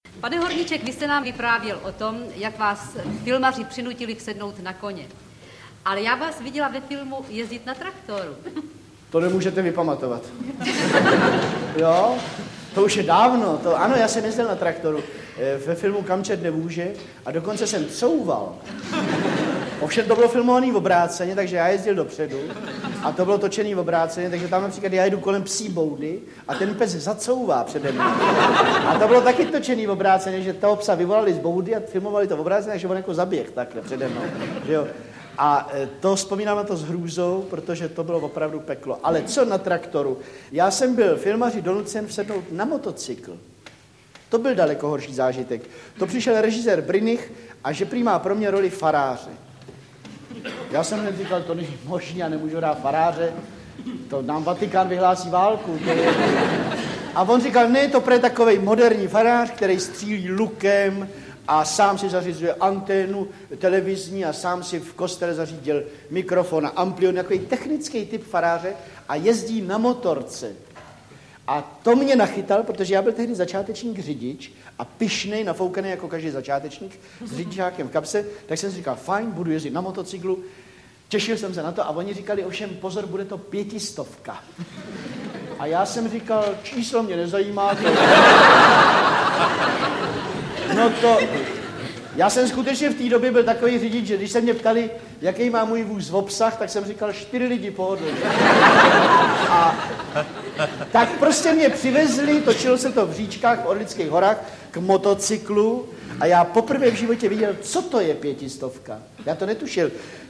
Hovory H audiokniha
Ukázka z knihy
Jeho pohotovost, schopnost improvizace, moudrost a nekonečná invence jsou nejlépe patrné z jeho Hovorů H natáčených koncem 60. let minulého století pro televizi.
• InterpretMiroslav Horníček, Milan Lasica, Július Satinský, Jan Werich, Jiří Sovák, Vladimír Svitáček, Radúz Činčera